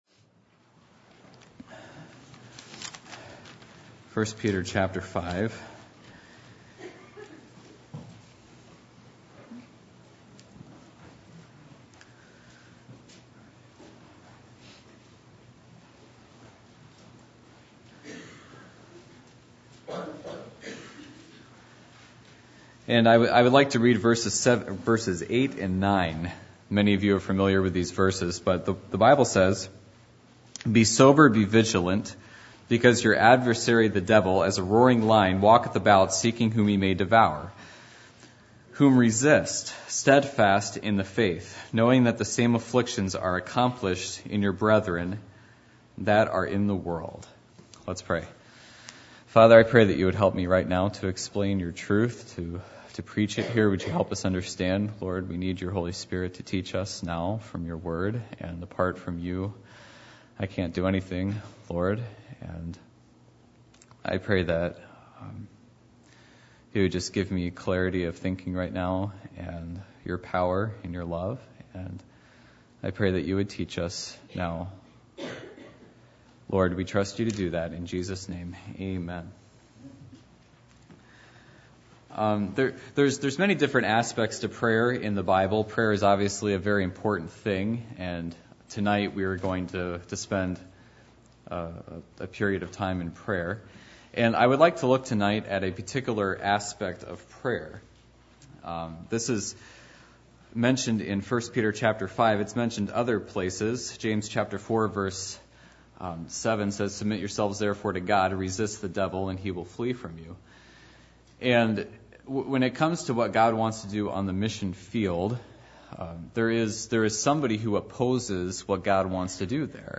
Ephesians 6:11-12 Service Type: Midweek Meeting %todo_render% « Except The Lord Build The House Results Of “Faith” As Found In The New Testament